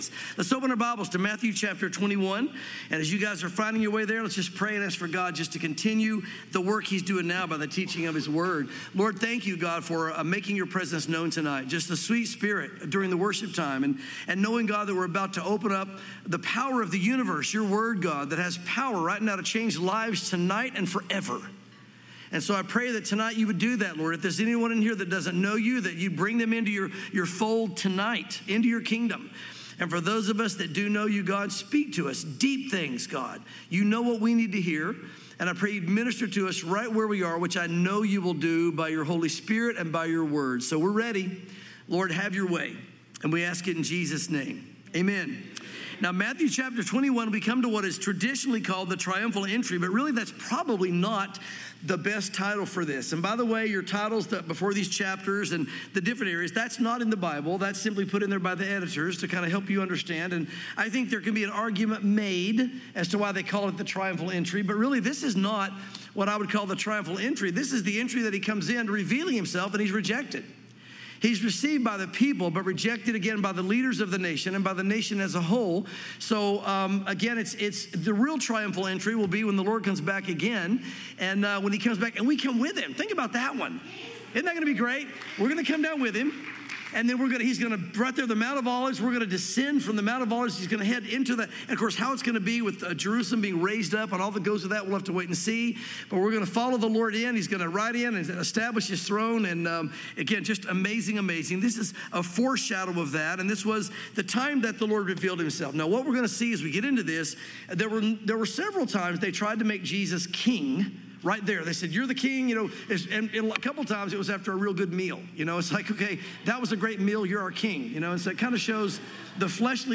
sermons Matthew 21-22:14